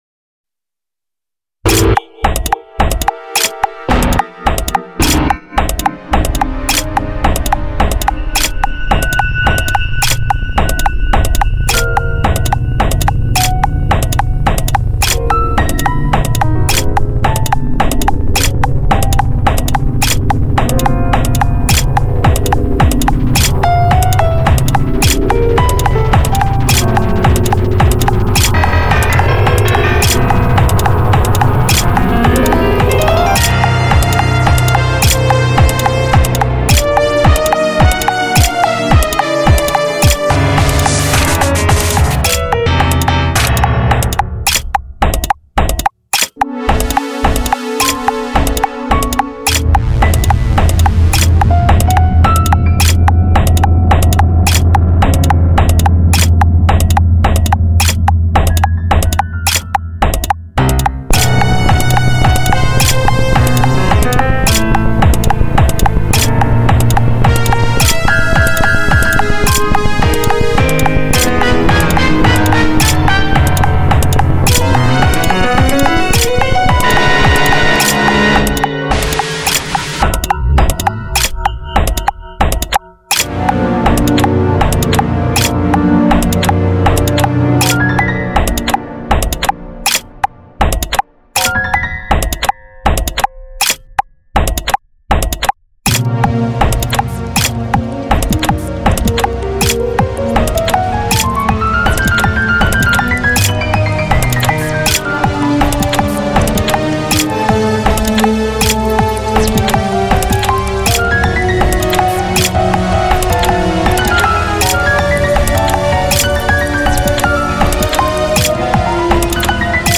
Backtrack with Click and Piano
EverythingFallsAway_BackTrack_With_Click_and_Piano.mp3